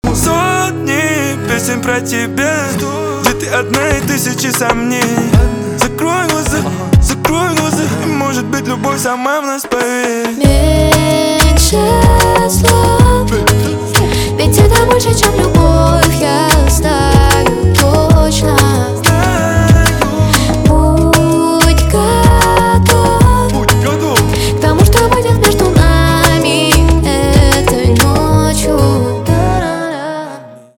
поп
гитара
чувственные